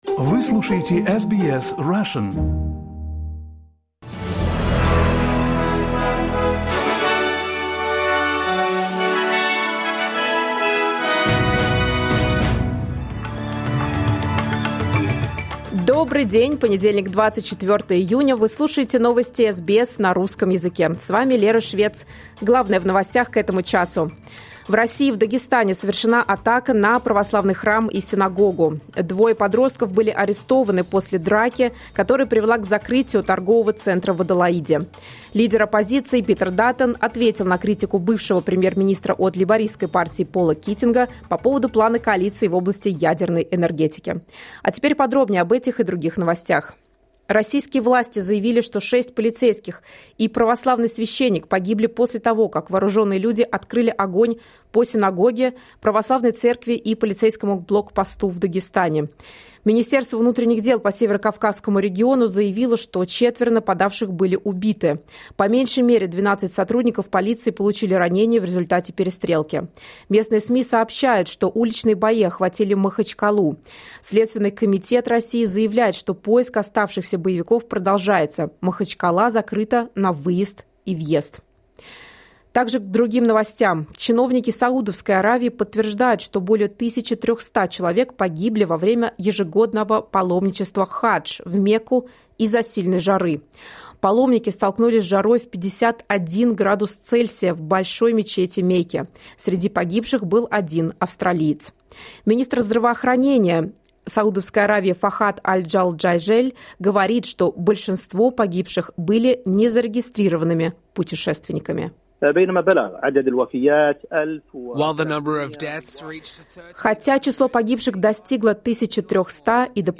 SBS News in Russian — 24.06.2024